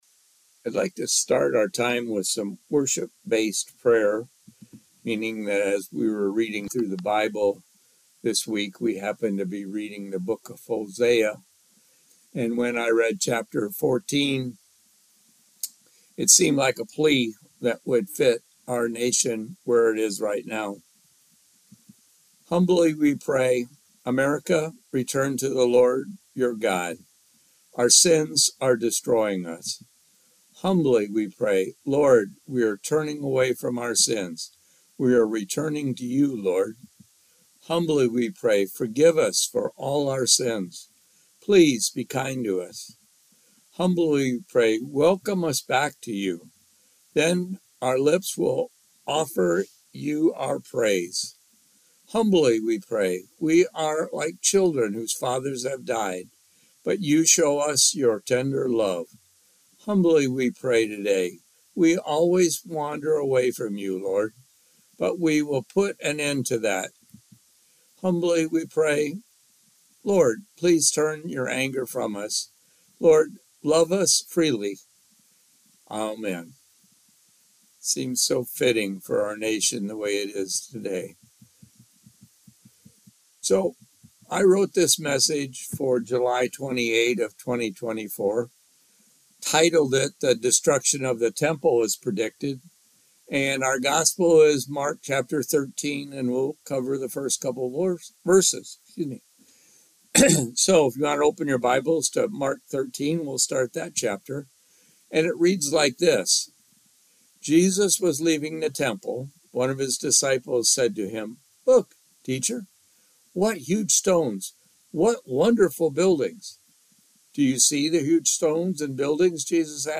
Coleta UBC Sermon Audio 2024